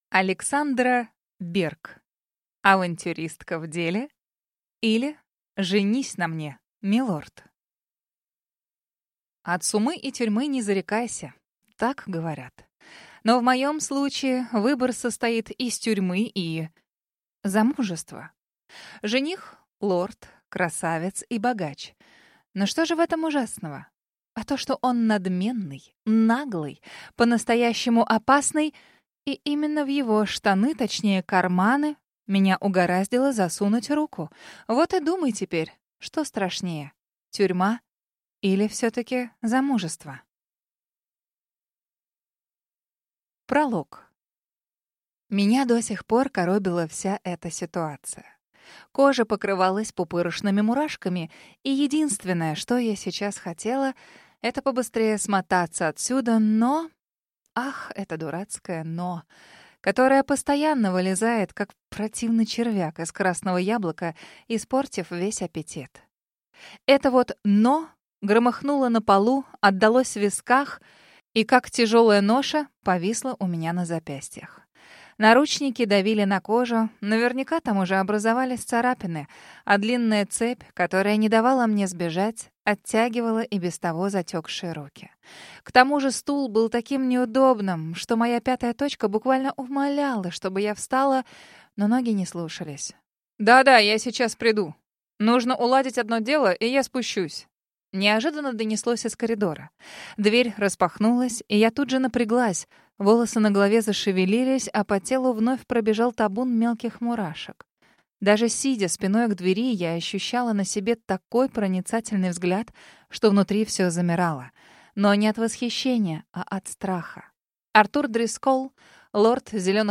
Аудиокнига Авантюристка в деле, или Женитесь на мне милорд!
Прослушать и бесплатно скачать фрагмент аудиокниги